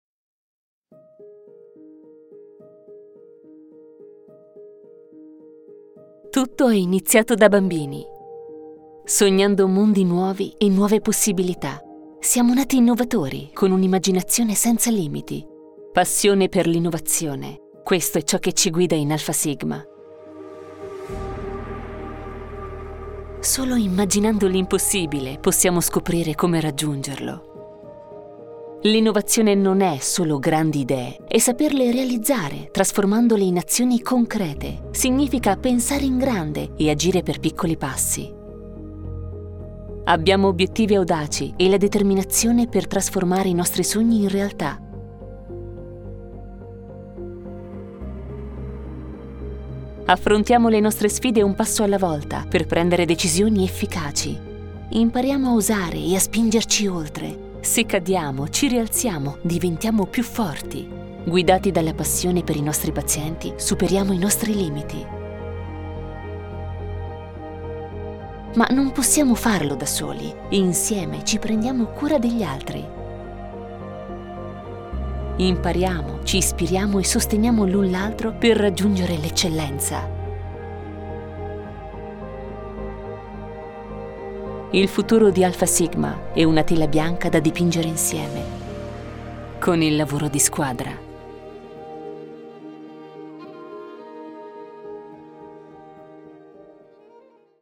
Female
Soft voice, warm, intense, suitable for institutional intonations, but also smiling and solar, dynamic and sparkling.
Corporate